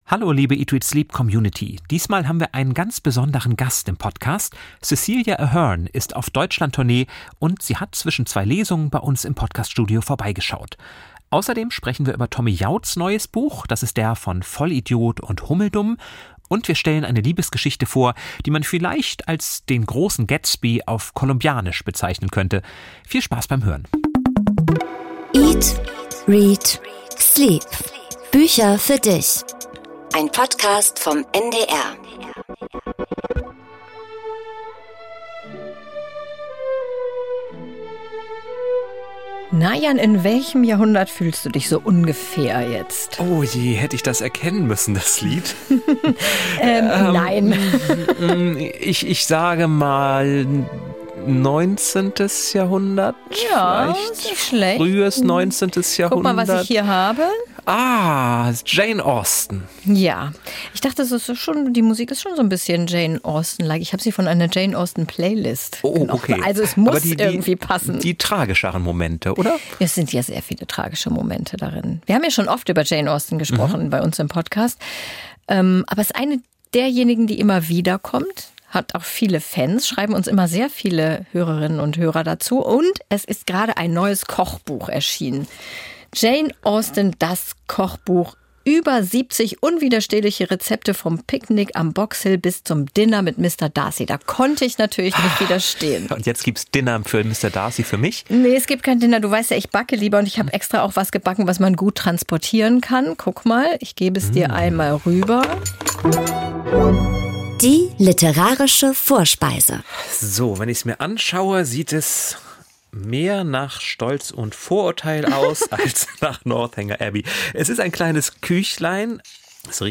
Die Bestsellerautorin erzählt im Studio davon, wie bunt das Leben sein kann.